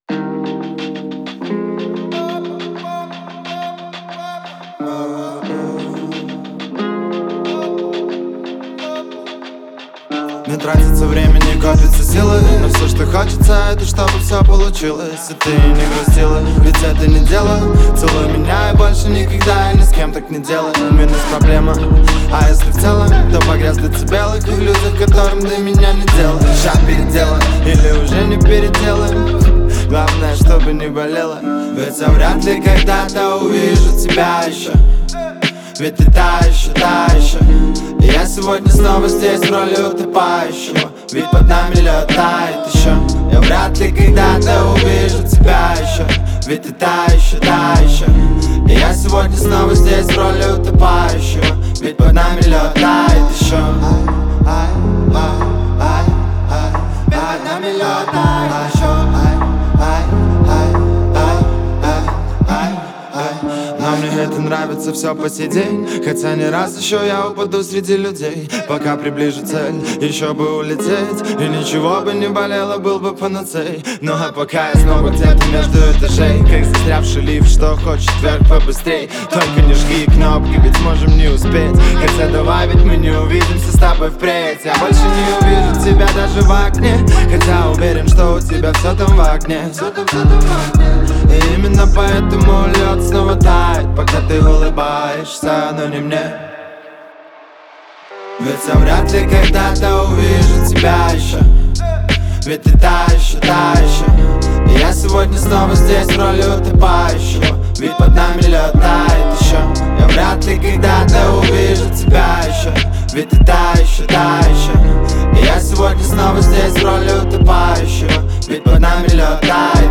Настроение трека меланхоличное, но в то же время светлое.